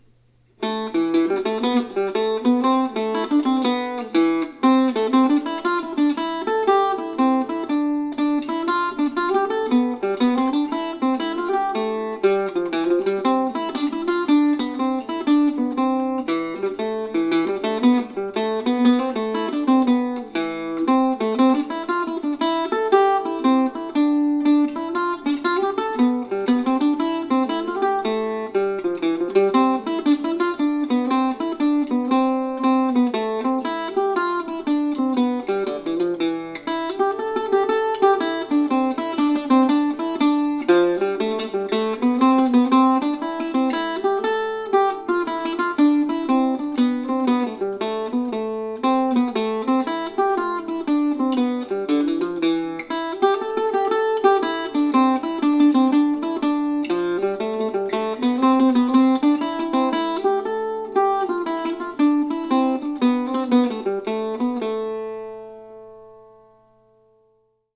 for mandocello or octave mandolin.